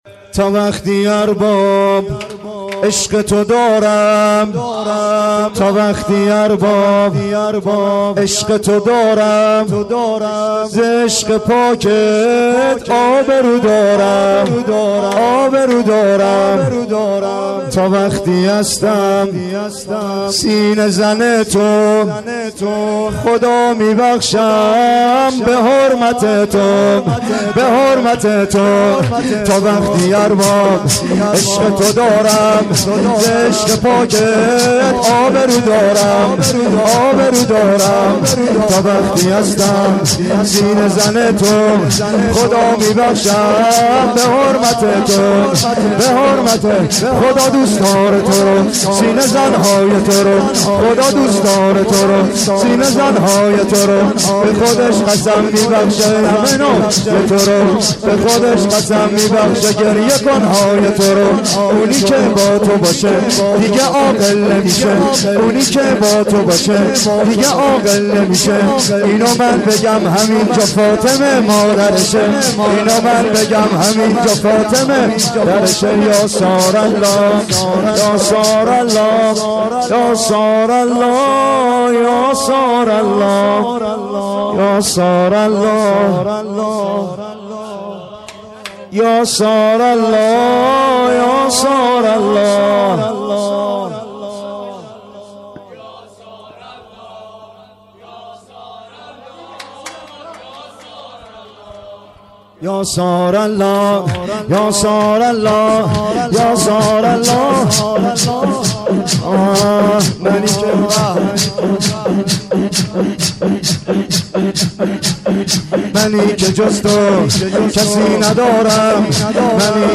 تا وقتی ارباب عشقتُ دارم (سینه زنی/شور